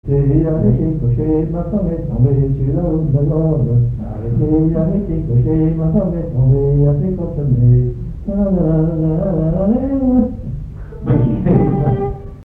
Enfantines - rondes et jeux
danse : mazurka
Airs à danser aux violons et deux chansons
Pièce musicale inédite